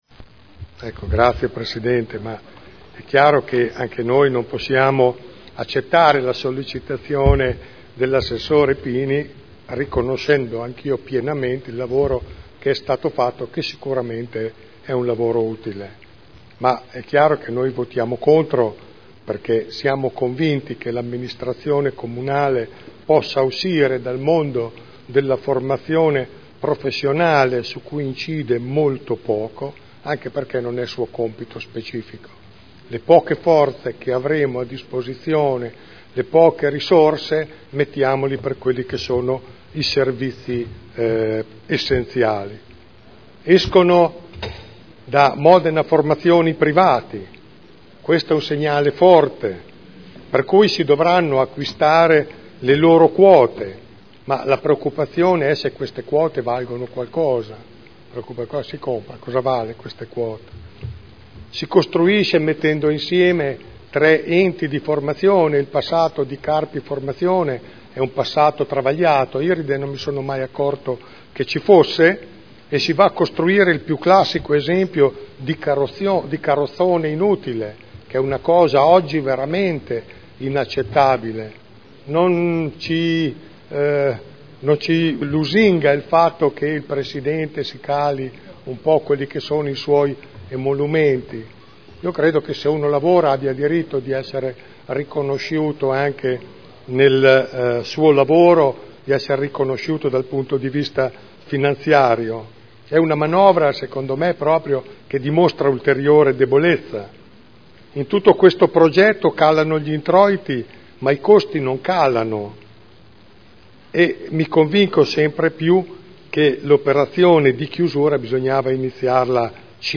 Gian Carlo Pellacani — Sito Audio Consiglio Comunale